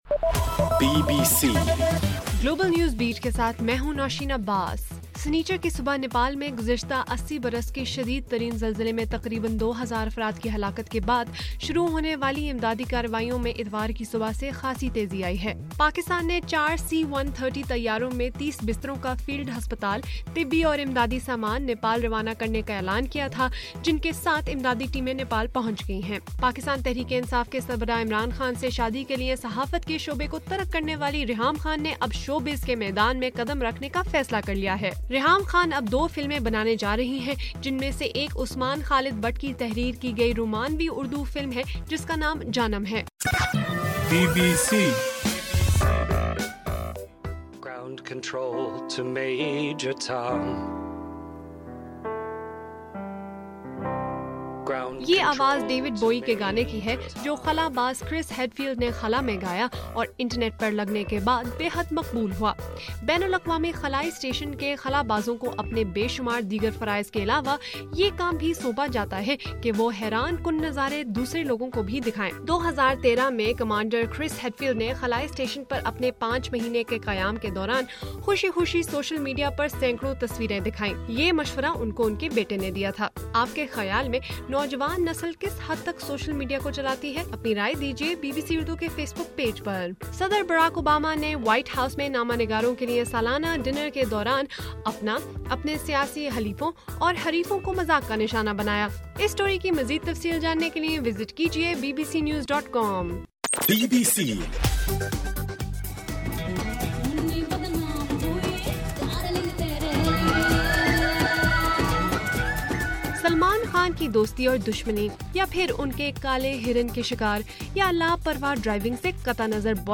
اپریل 26: رات 9 بجے کا گلوبل نیوز بیٹ بُلیٹن